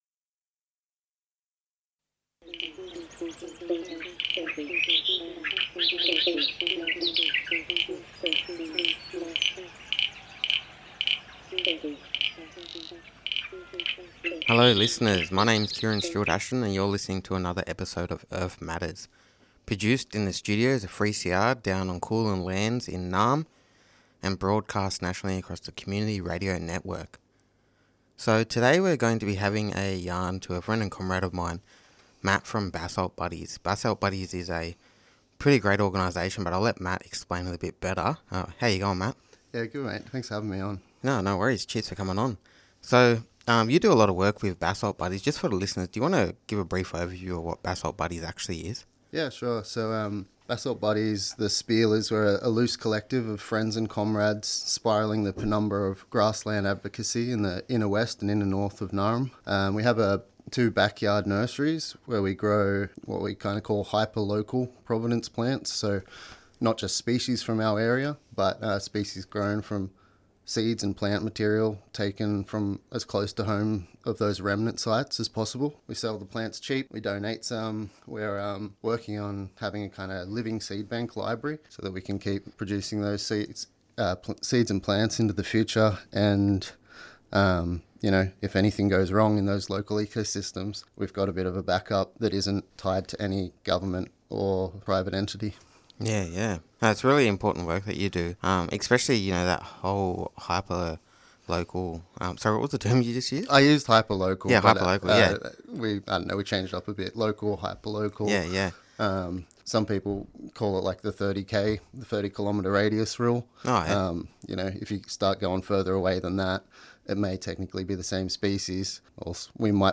Interview with Basalt Buddies Pirate Nursery